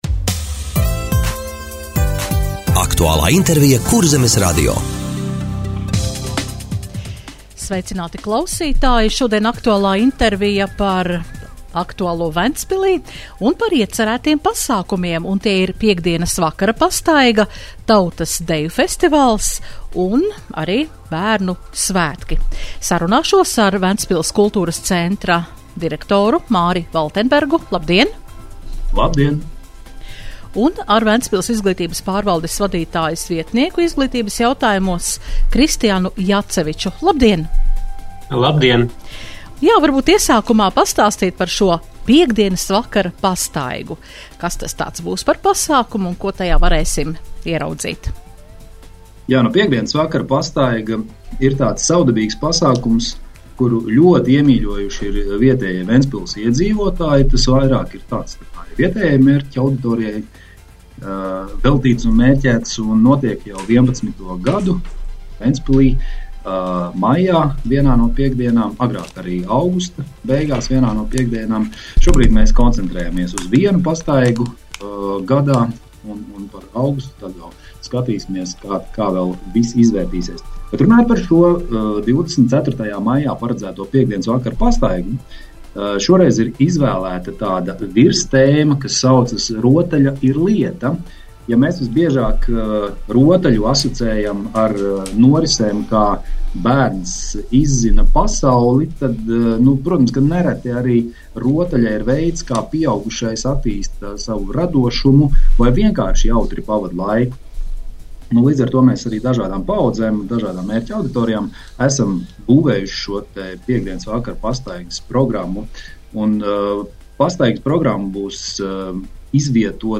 Radio saruna Kultūras pasākumu piedāvājums Ventspilī - Ventspils